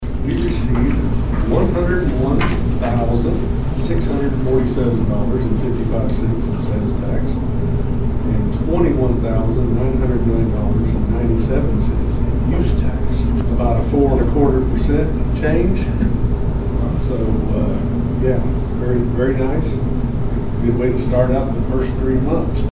The Dewey City Council convened on Monday evening at Dewey City Hall for the final time in September.
City Manager Kevin Trease gave the report.